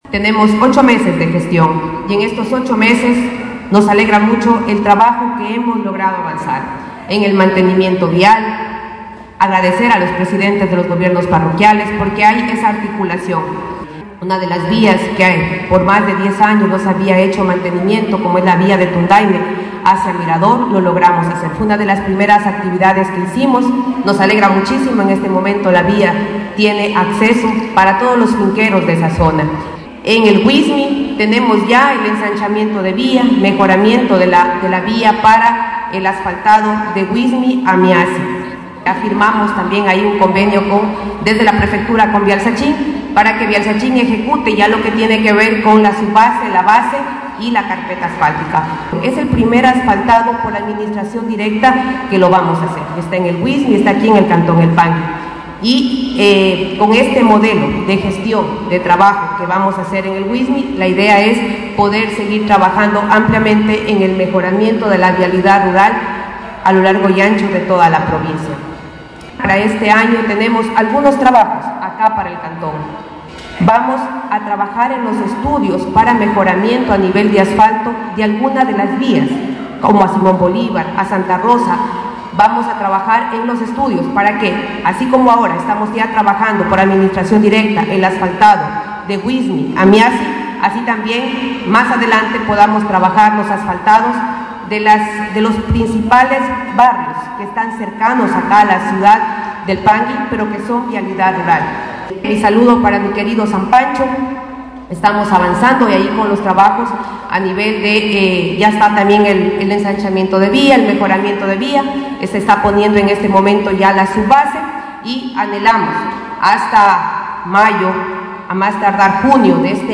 Al conmemorarse los 33 años de vida cantonal de la Orquídea de la Amazonía, El Pangui, la prefecta Karla Reátegui en representación de la Prefectura de Zamora Chinchipe, participó de los actos festivos de este cantón, donde fueron firmados varios acuerdos de cooperación interinstitucional que se ejecutarán en este 2024, lo que significa un aporte económico total de alrededor de $ 982.054,00, desde la entidad provincial.
KARLA REÁTEGUI, PREFECTA
KARLA-REATEGUI-PREFECTA-1.mp3